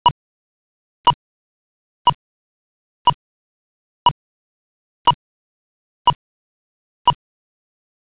Accessible Pedestrian Signals: Pushbutton locator tone
Locator_tone_3.wav